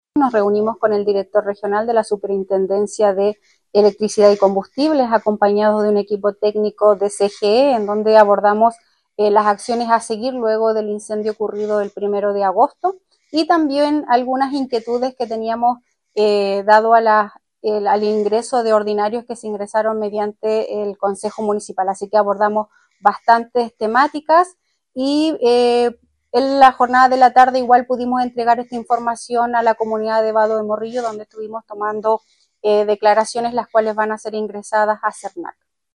La alcaldesa subrogante comentó el tenor de la reunión y el trabajo realizado por el municipio.
Cuna-alcaldesa-subrogante.mp3